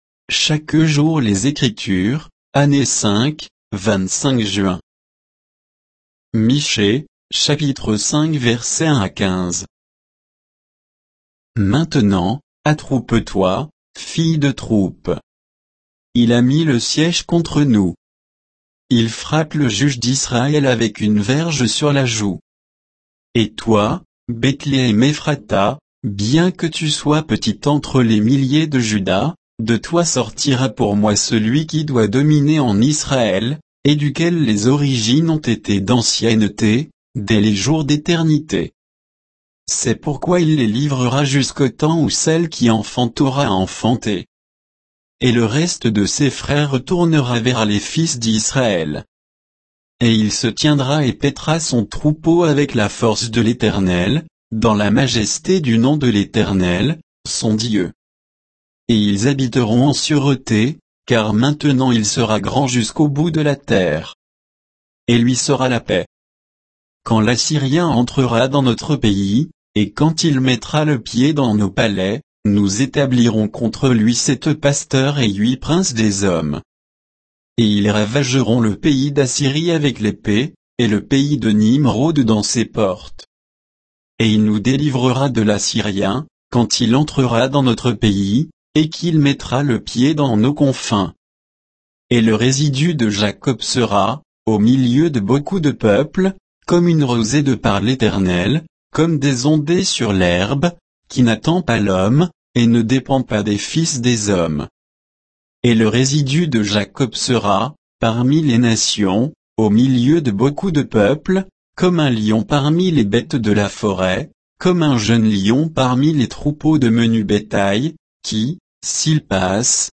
Méditation quoditienne de Chaque jour les Écritures sur Michée 5